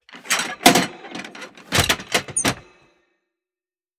Ammo Crate Epic 002.wav